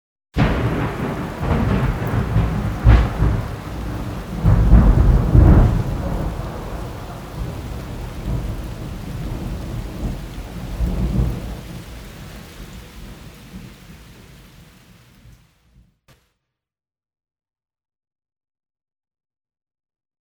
Recorded in Felina (Reggio Emilia, Italy) in august 2003